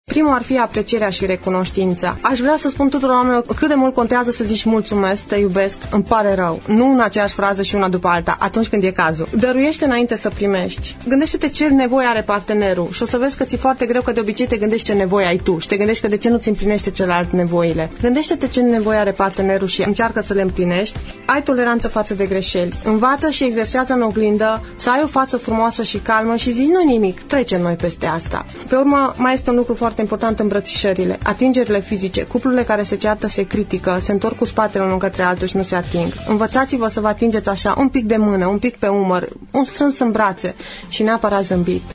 extras emisiunea Părerea ta